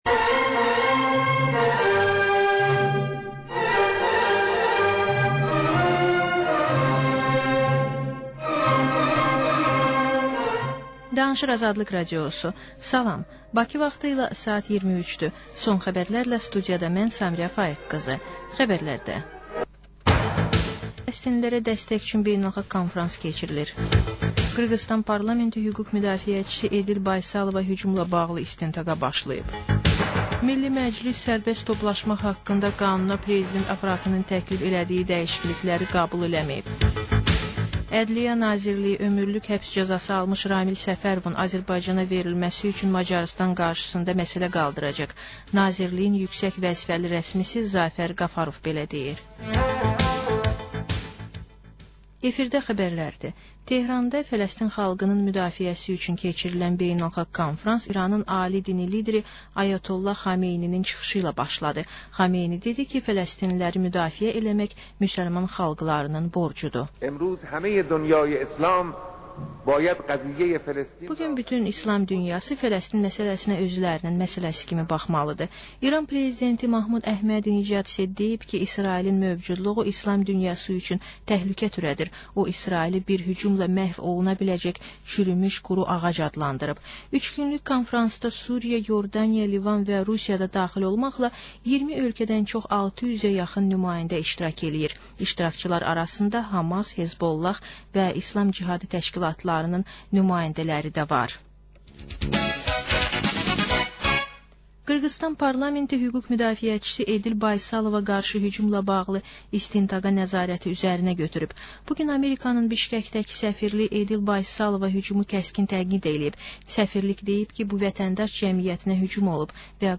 Xəbərlər, reportajlar, müsahibələr. Və: Dəyirmi masa söhbəti.